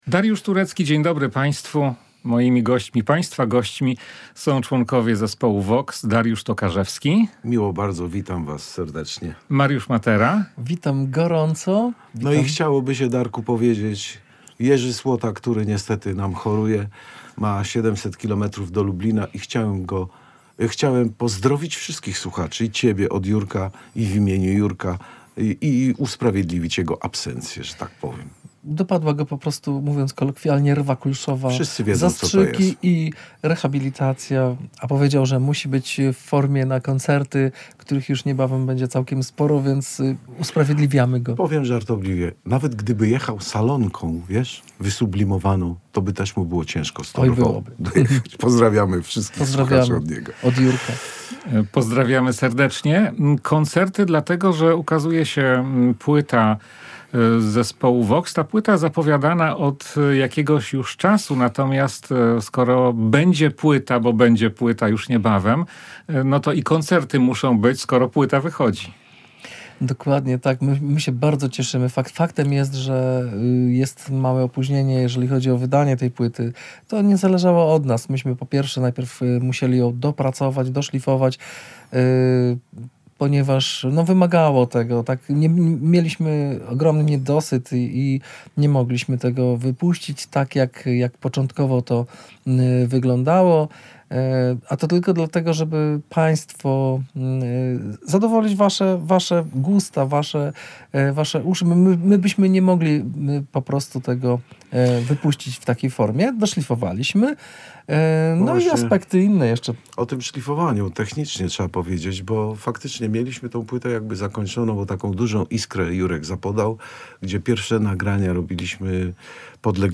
w rozmowie